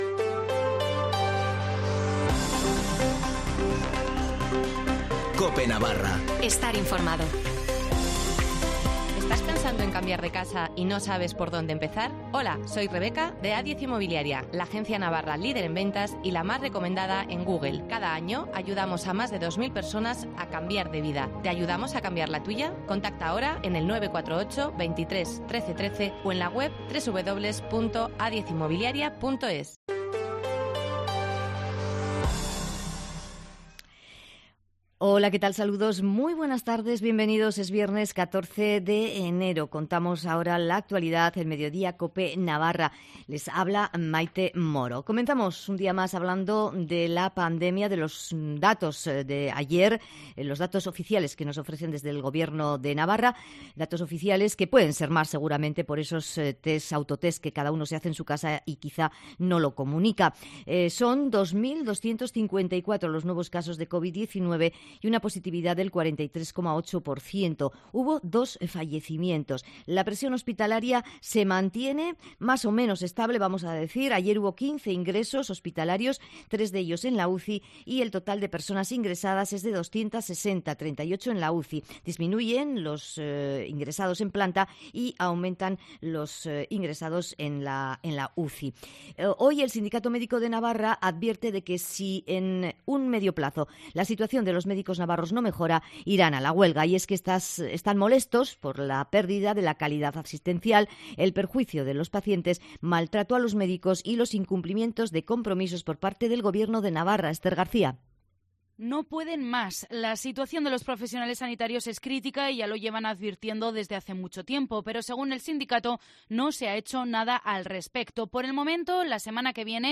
Informativo de las 14:20 en Cope Navarra (14/01/2022)